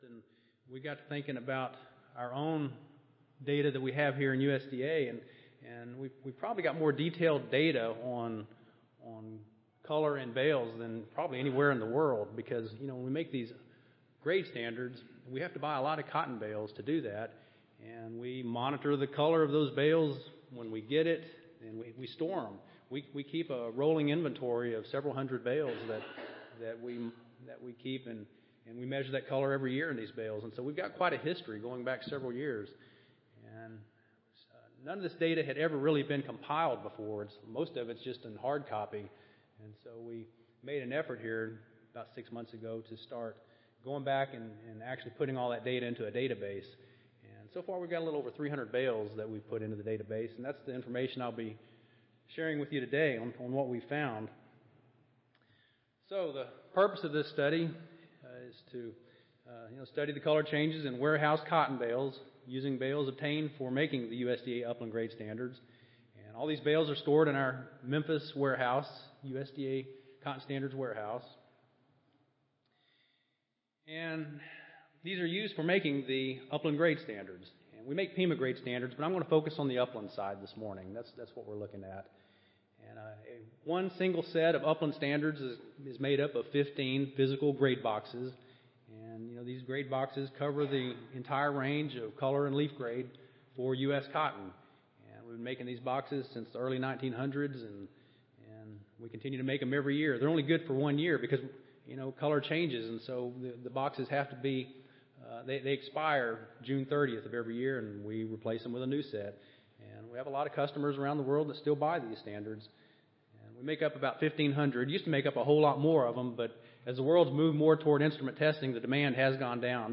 Cotton & Tobacco Program Audio File Recorded Presentation Color drift was studied for over 300 bales of cotton covering the full range of Upland cotton color grades.